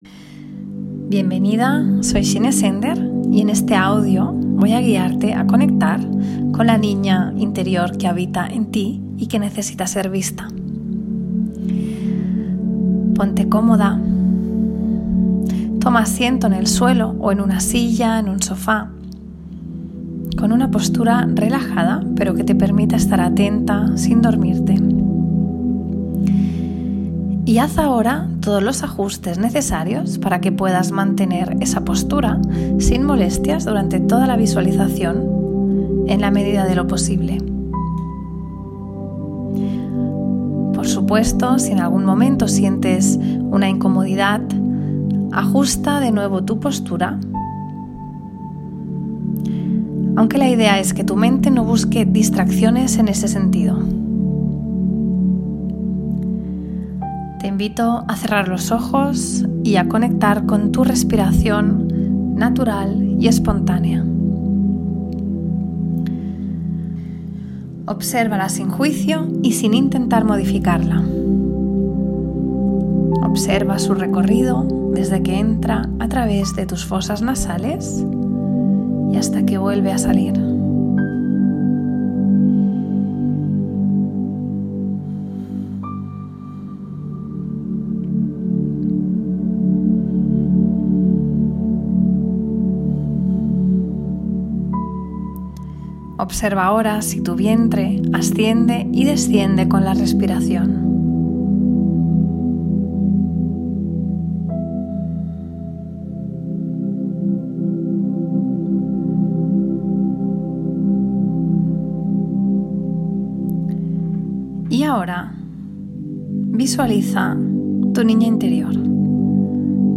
volumen moderado